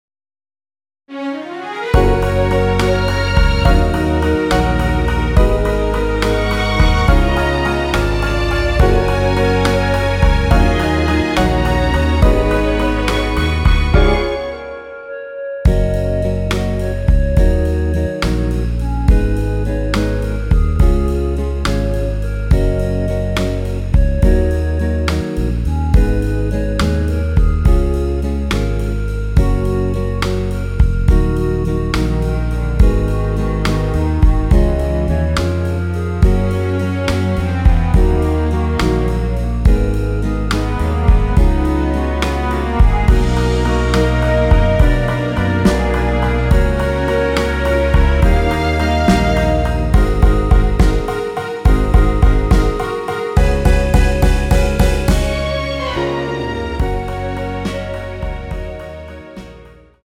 원키 멜로디 포함된 MR입니다.(미리듣기 확인)
F#
노래방에서 노래를 부르실때 노래 부분에 가이드 멜로디가 따라 나와서
앞부분30초, 뒷부분30초씩 편집해서 올려 드리고 있습니다.
중간에 음이 끈어지고 다시 나오는 이유는